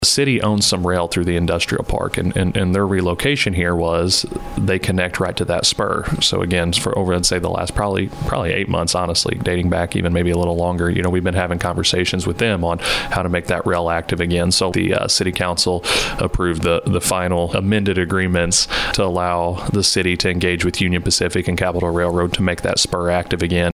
City Administrator for Park Hills, Zach Franklin, says this will help with future growth for the city.